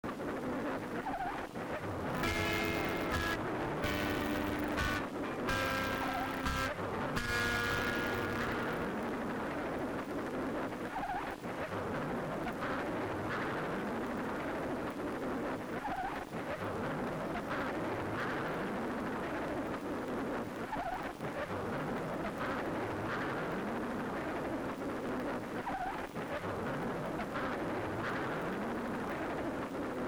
読まづ雑音のみ、ギター信号入れ 再生ヘッド から聞えないのも変だがの、テープの摩擦音が聴こえてる、ヨレ磁気
Noize.mp3